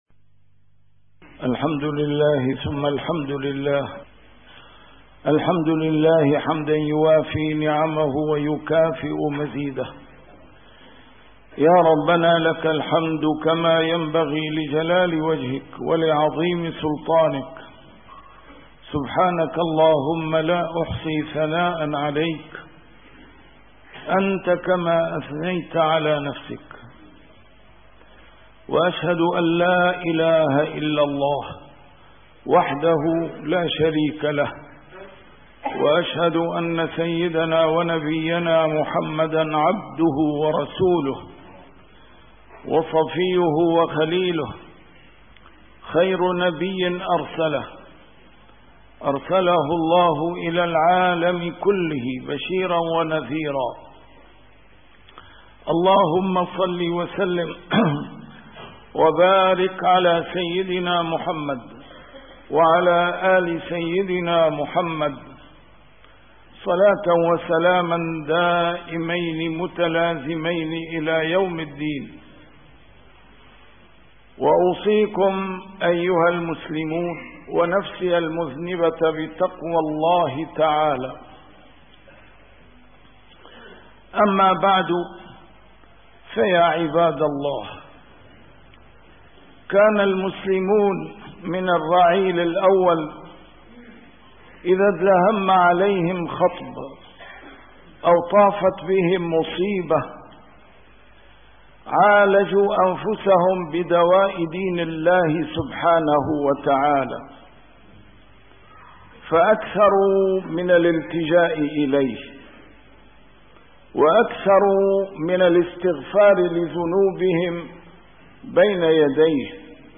A MARTYR SCHOLAR: IMAM MUHAMMAD SAEED RAMADAN AL-BOUTI - الخطب - علاج مشكلاتنا بالصلاة على رسول الله صلى الله عليه وسلم